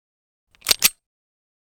aps_unjam.ogg